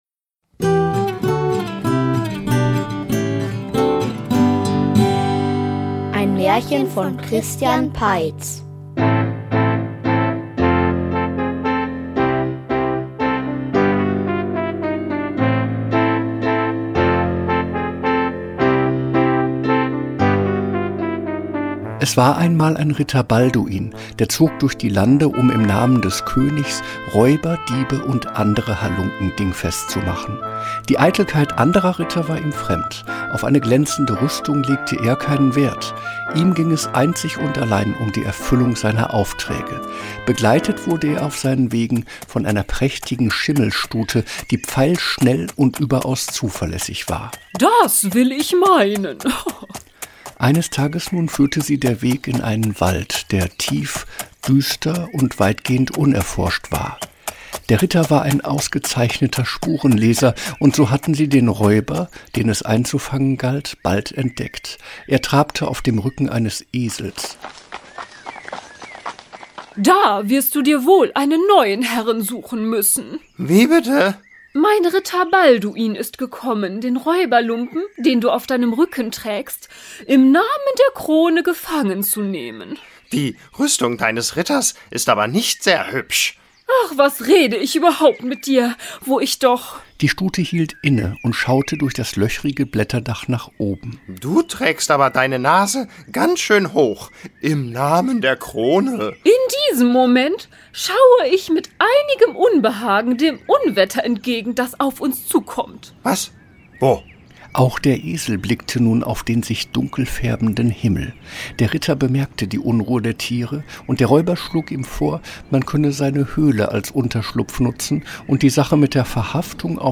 Im Namen der Krone --- Märchenhörspiel #61 ~ Märchen-Hörspiele Podcast